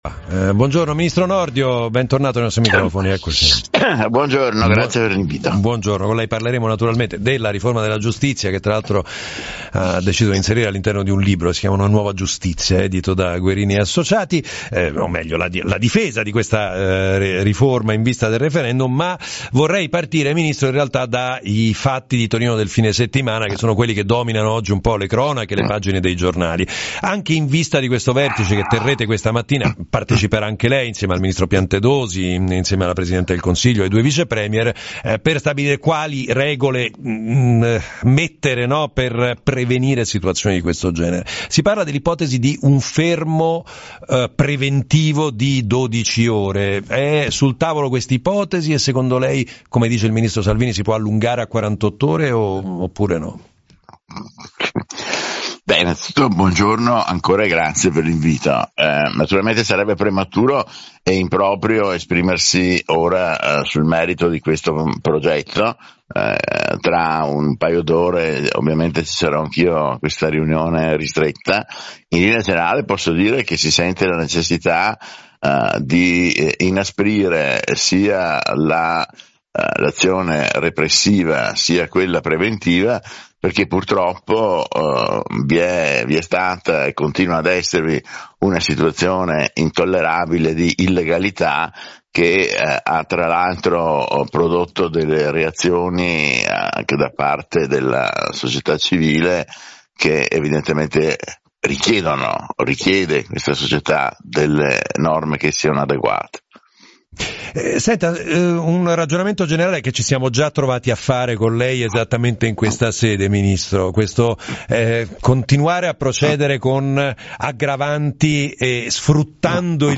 Il ministro della Giustizia Carlo Nordio è intervenuto ai microfoni di Radio 24, affrontando alcuni dei principali temi di attualità legati all’ordine pubblico e al funzionamento del sistema giudiziario.